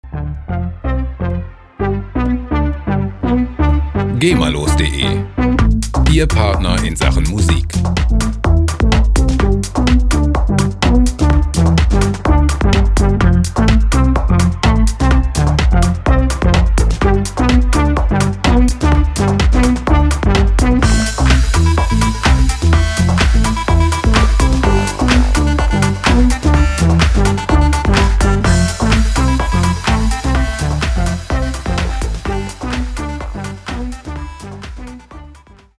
freie Techno Loops
Musikstil: Tech House
Tempo: 126 bpm